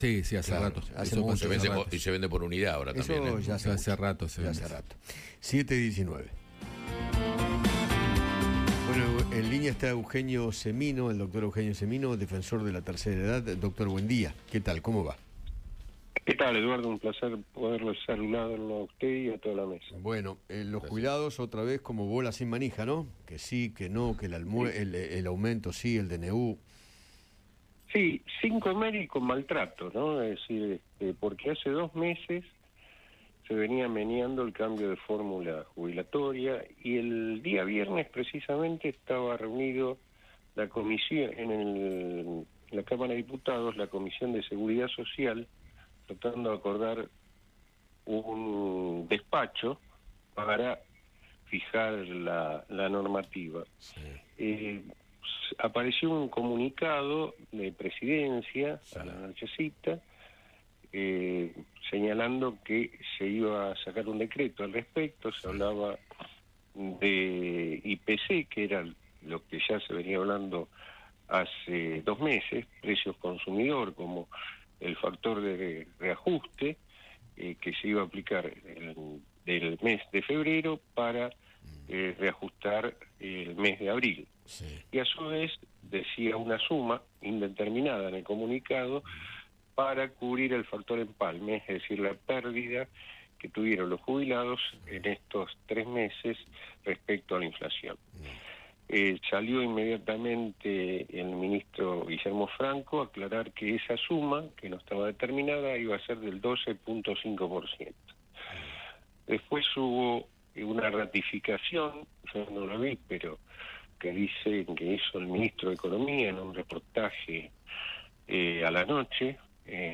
Eugenio Semino, defensor de la Tercera Edad, dialogó con Eduardo Feinmann sobre el nuevo decreto del Gobierno para modificar la fórmula de movilidad jubilatoria y que los haberes se actualicen según el IPC mensual.